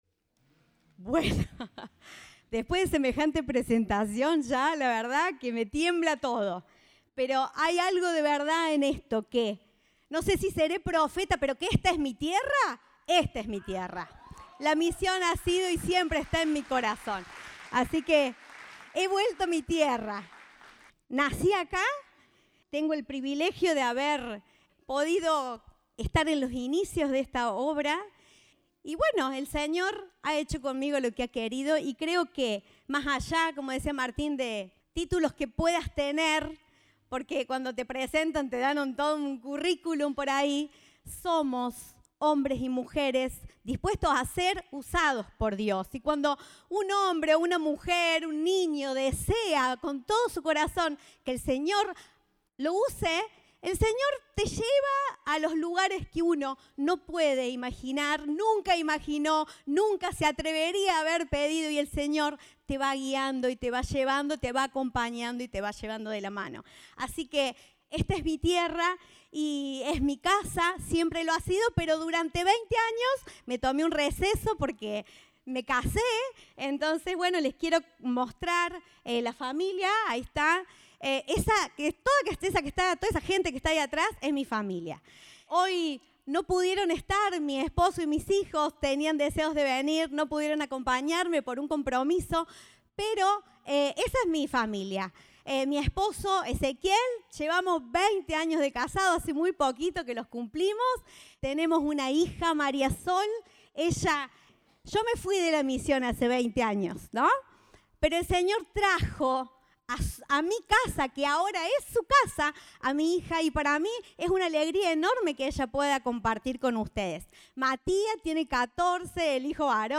Compartimos el mensaje del Domingo 30 de Julio de 2023 Orador invitado